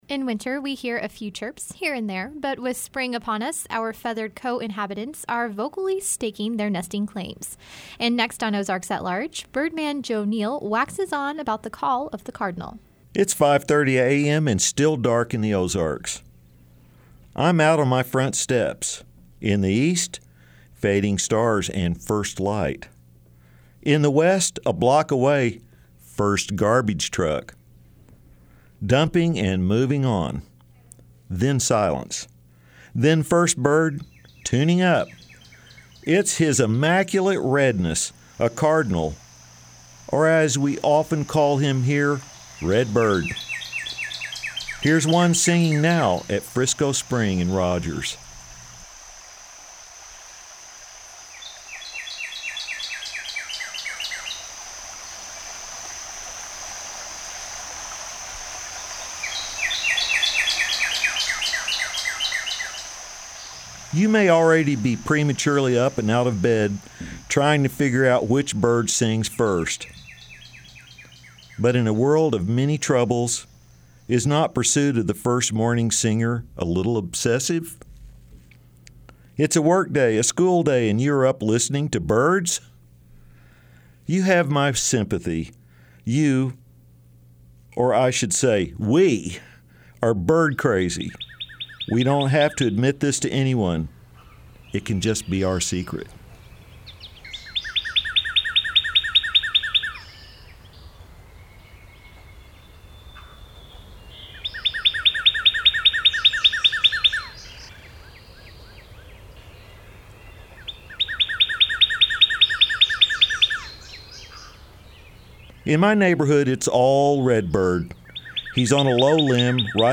In winter we hear a few chirps, but with spring upon us our feathered co-inhabitants are vocally staking their nesting claims.